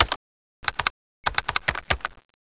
c4_plant1.wav